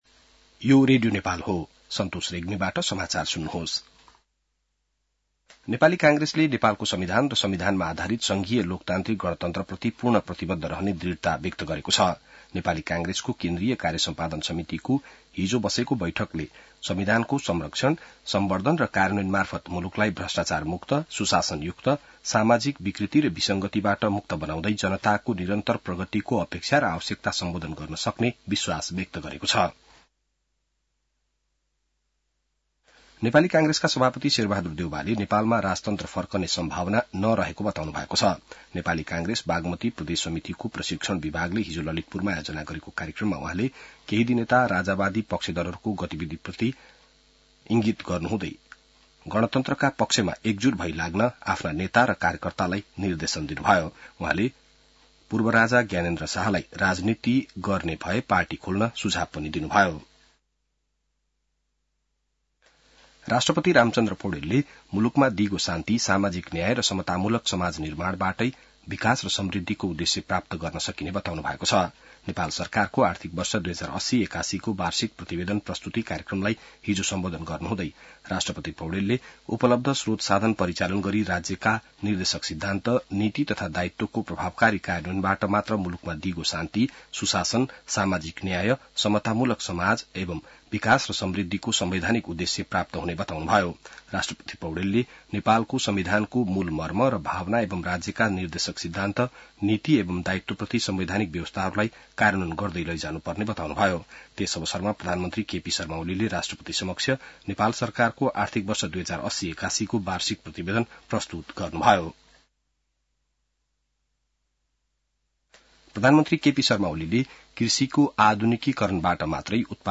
बिहान ६ बजेको नेपाली समाचार : ११ चैत , २०८१